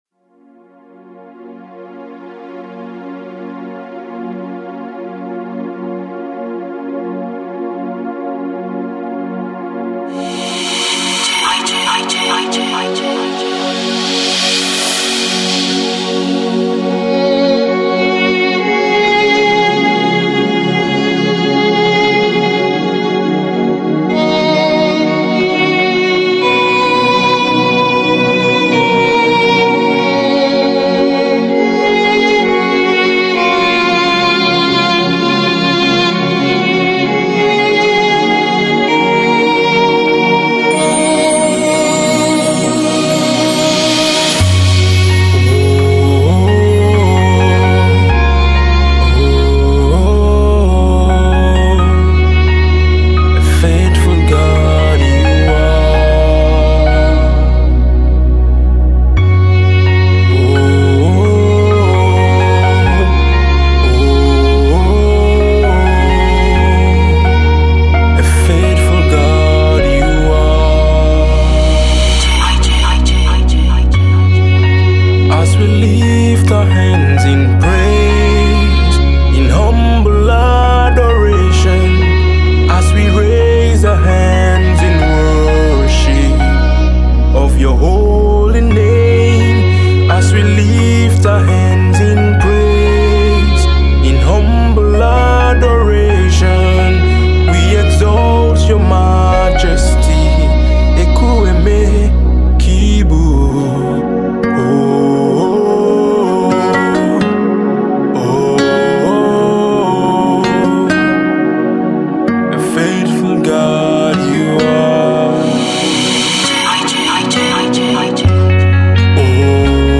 France based Nigerian Gospel Music Artiste